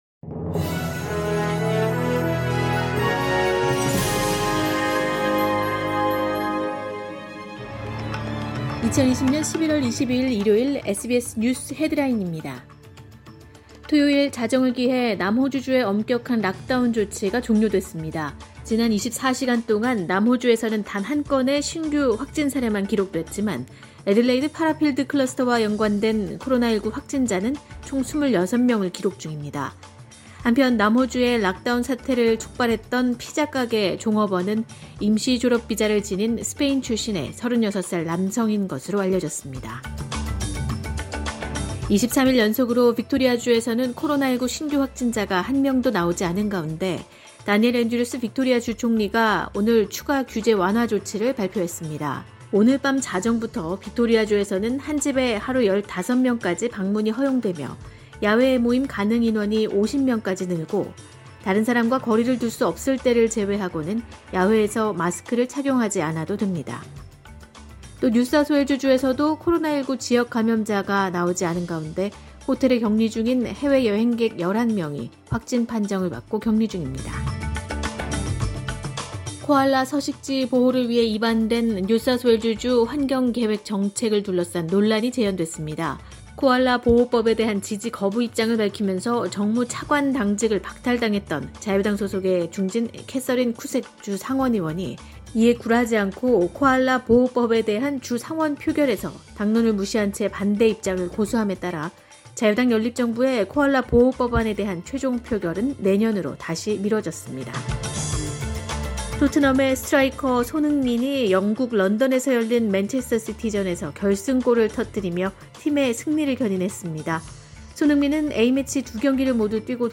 SBS News Headlines…2020년 11월 22일 오전 주요 뉴스